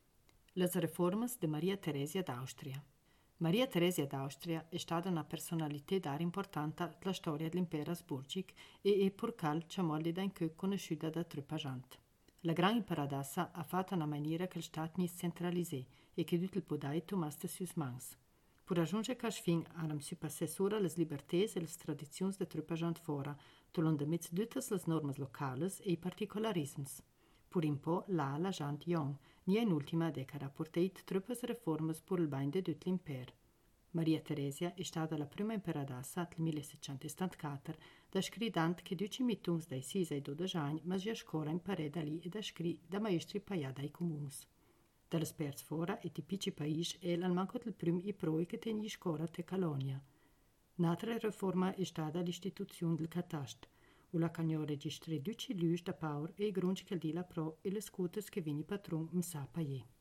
Ladino badiota